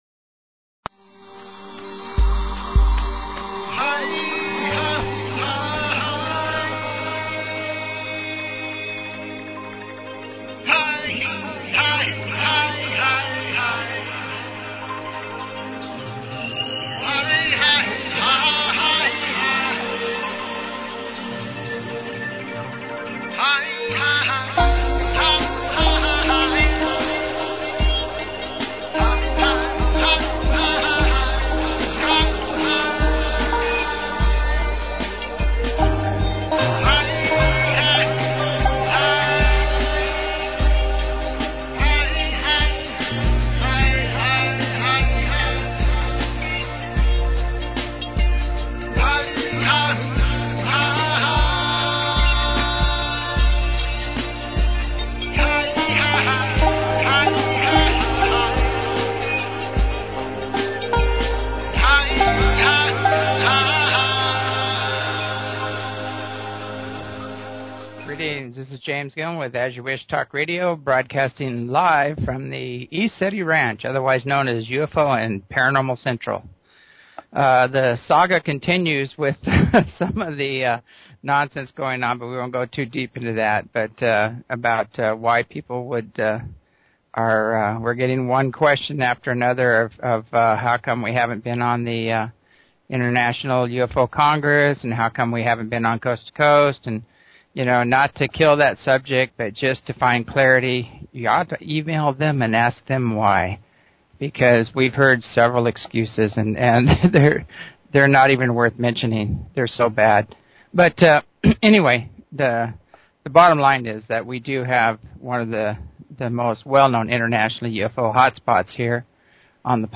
Talk Show Episode, Audio Podcast, As_You_Wish_Talk_Radio and Courtesy of BBS Radio on , show guests , about , categorized as
ECETI Censorship, the ongoing saga. Callins